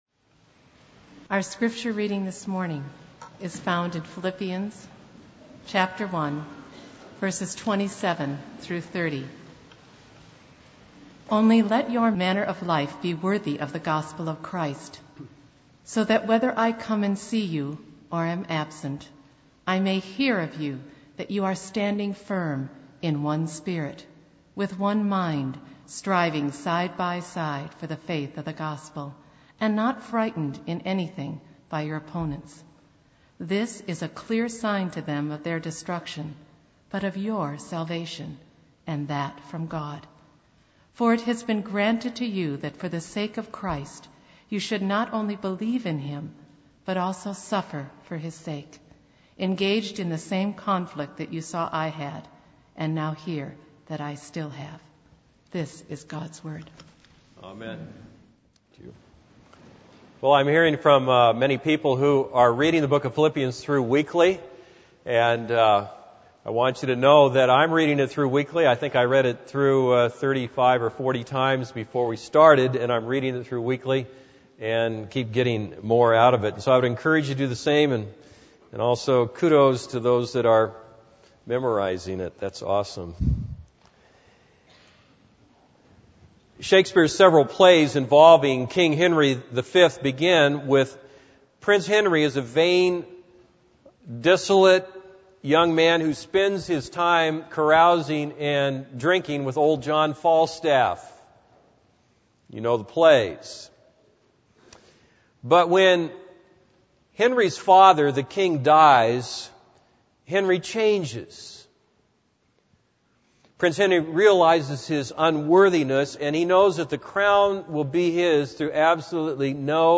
This is a sermon on Philippians 1:27-30.